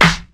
• 00's Hip-Hop Snare Drum Sample E Key 17.wav
Royality free steel snare drum sample tuned to the E note. Loudest frequency: 1833Hz
00s-hip-hop-snare-drum-sample-e-key-17-Ib8.wav